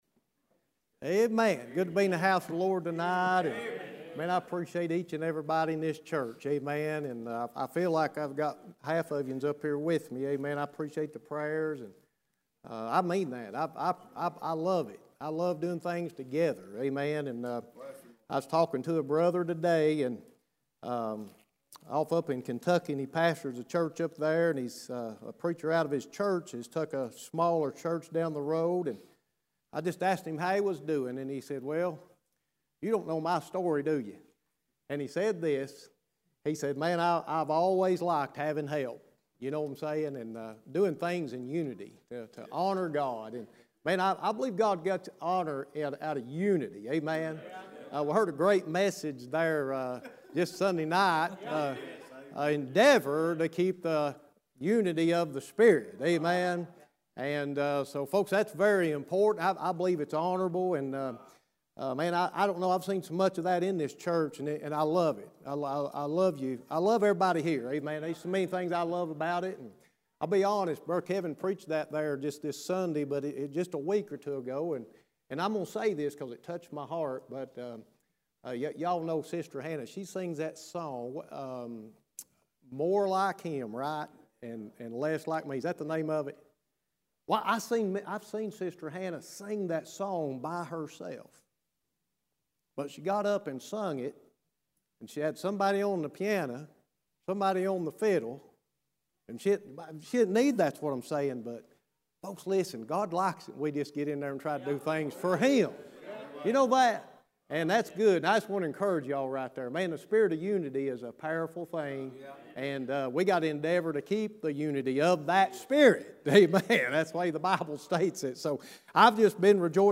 Passage: Exodus 5:1; 10:8-9 Service Type: Wednesday Evening